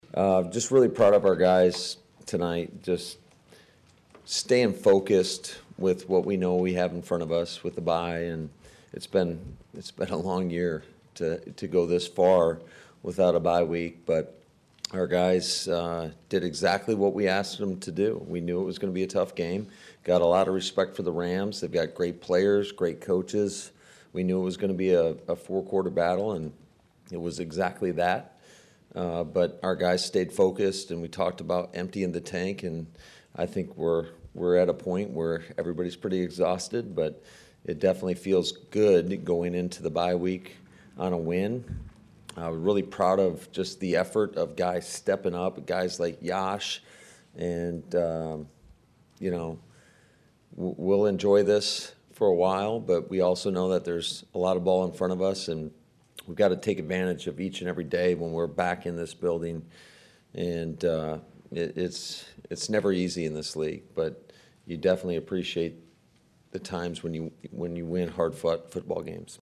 But considering just how weary the team was with it’s 12th consecutive game,  Head Coach Matt LaFleur’s post game comments was all about resiliency once again.